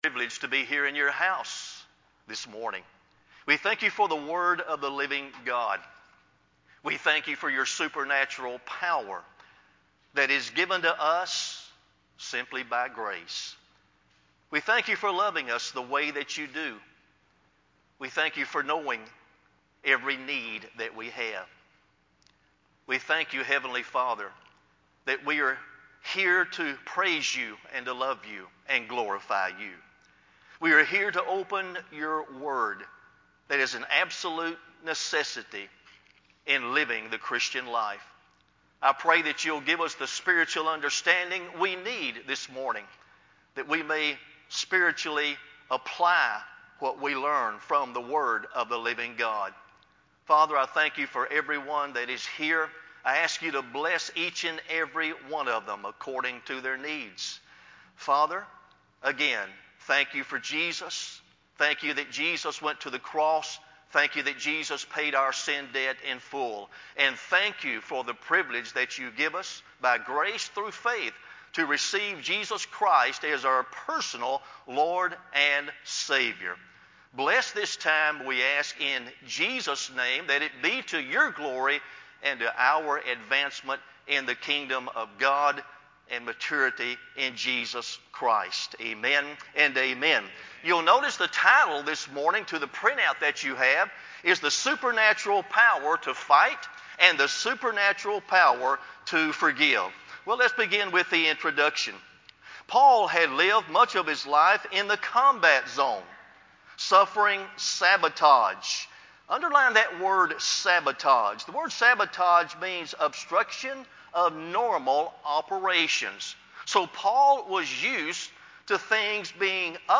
sermon-9-27-CD.mp3